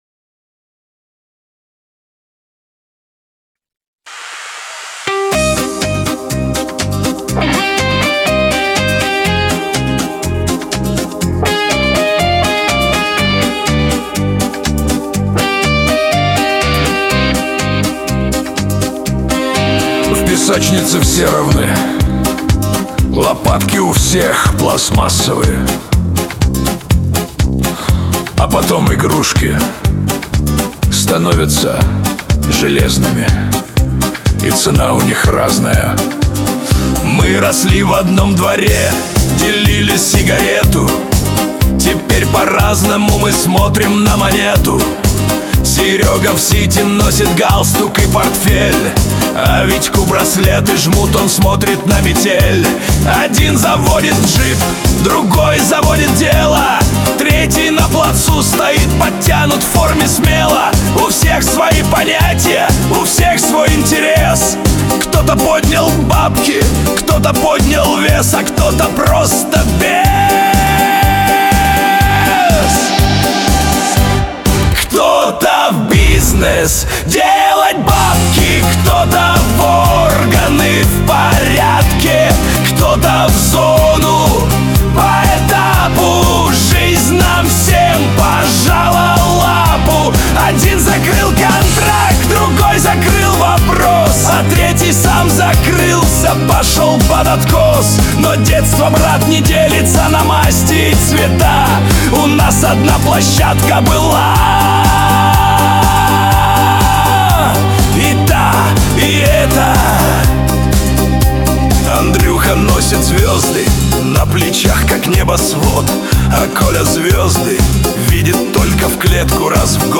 Лирика
pop
Шансон